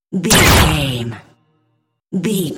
Dramatic stab laser
Sound Effects
Atonal
heavy
intense
dark
aggressive
hits